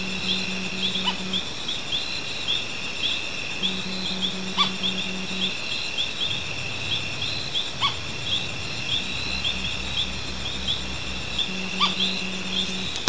Tropical Screech Owl (Megascops choliba)
Creo que voz de alarma
Location or protected area: Las Varillas
Condition: Wild